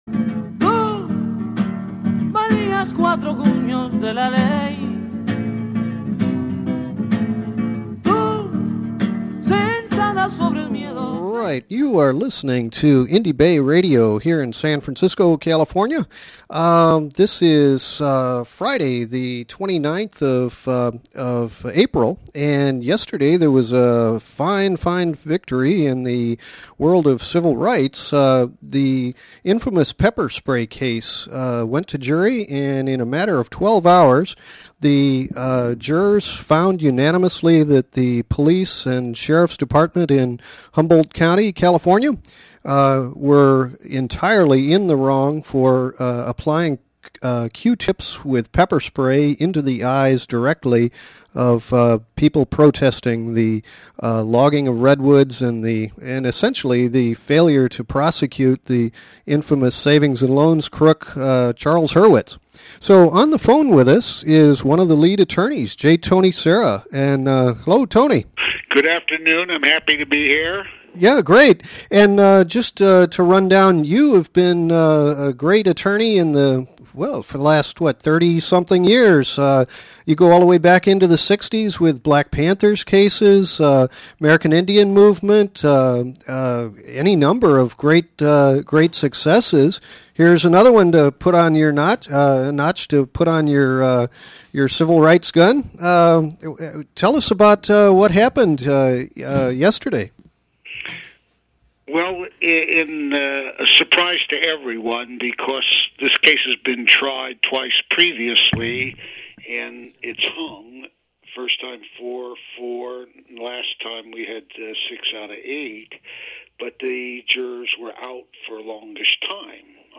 Interview with Attorney J. Tony Serra